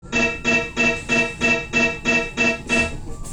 colision.wav